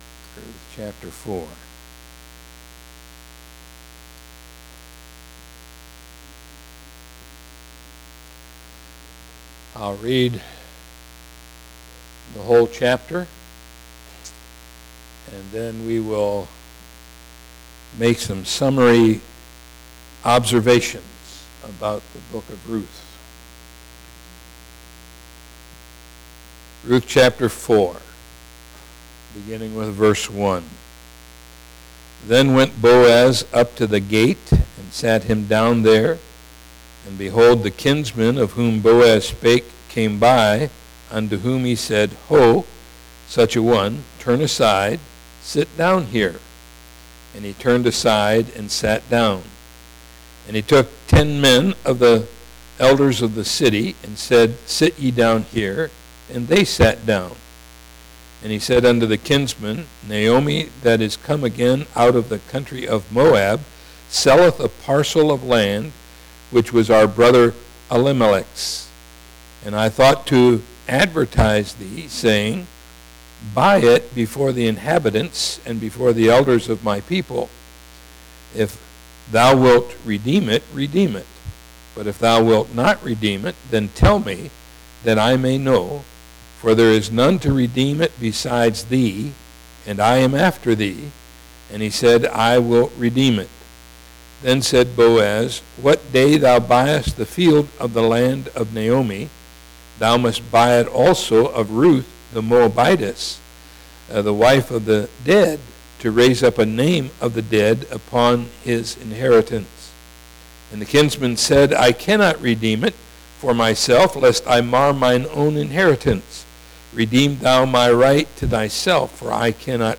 Scripture - Romans 5:12-21 When - Sunday Morning Service